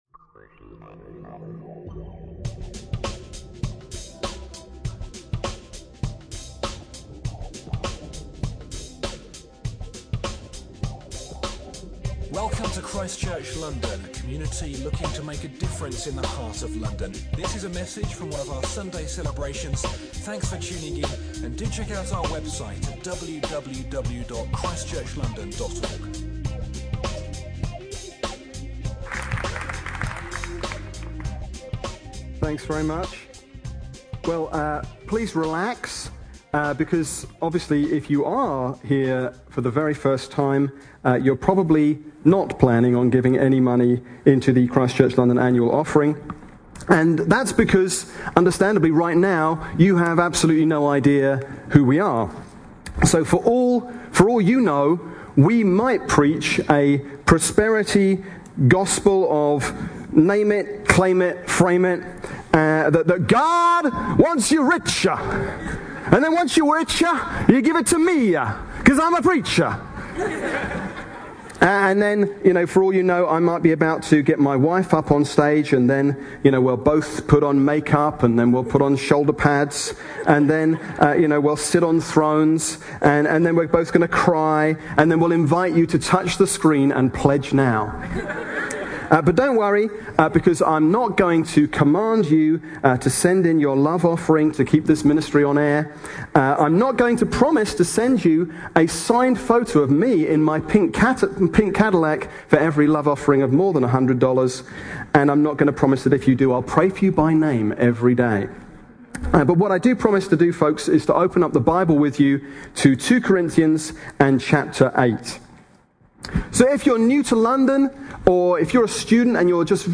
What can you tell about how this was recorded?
2 Corinthians 8 1-15 – Preaching from ChristChurch London’s Sunday Service